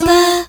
Note 4-F.wav